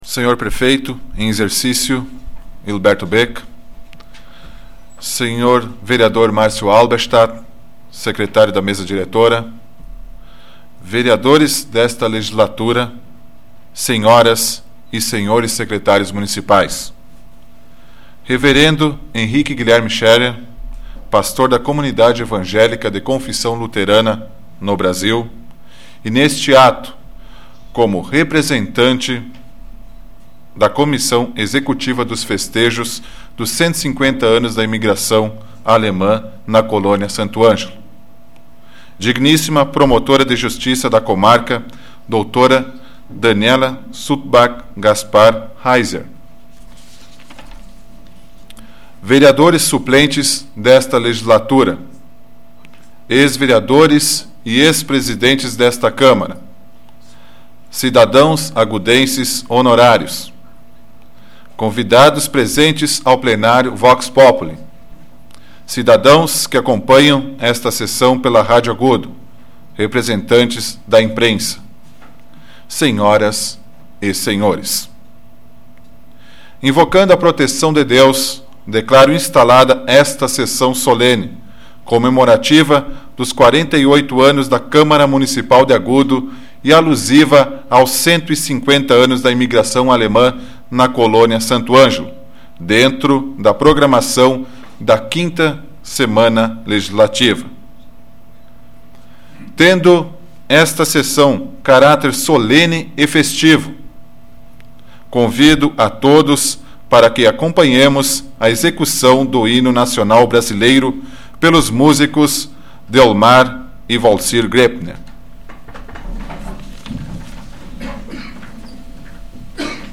Áudio da 6ª Sessão Solene da 12ª Legislatura, de 06 de junho de 2007